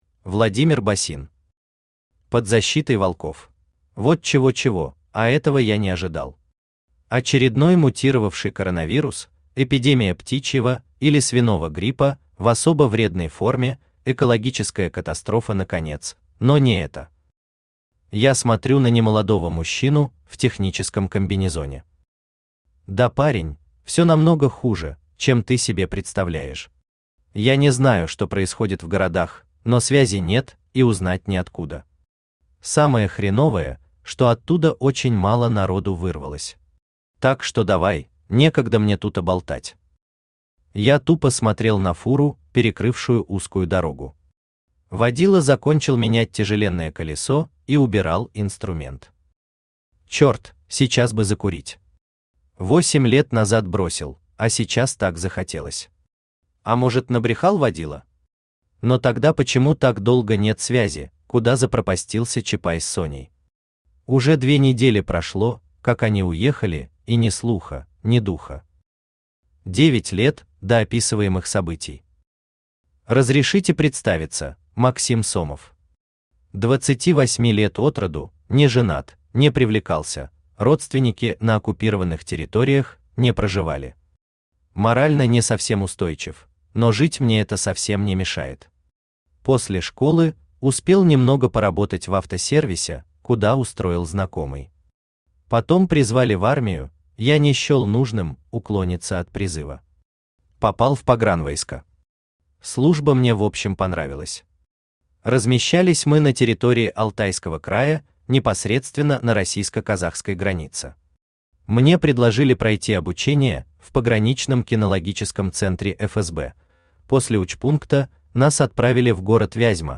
Аудиокнига Под защитой волков | Библиотека аудиокниг
Aудиокнига Под защитой волков Автор Владимир Георгиевич Босин Читает аудиокнигу Авточтец ЛитРес.